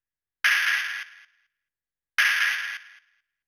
Index of /90_sSampleCDs/Best Service ProSamples vol.54 - Techno 138 BPM [AKAI] 1CD/Partition C/SHELL CRASHE
NOIZY     -R.wav